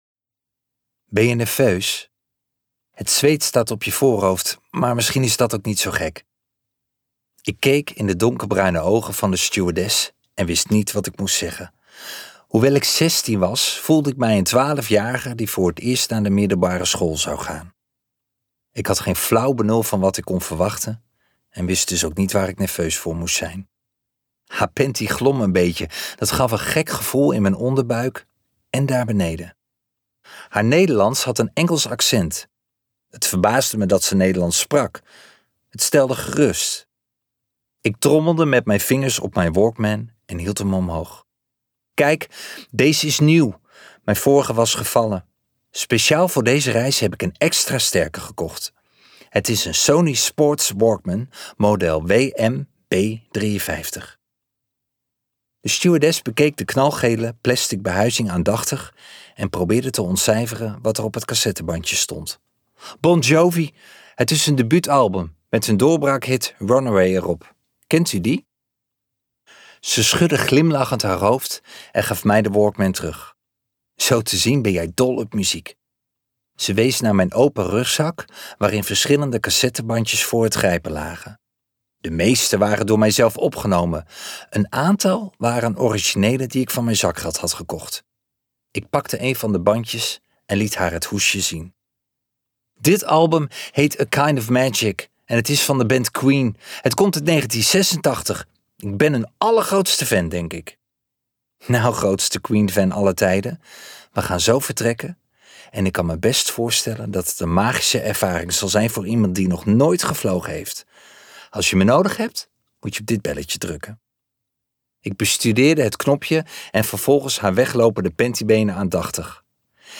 Liften naar de hemel luisterboek | Ambo|Anthos Uitgevers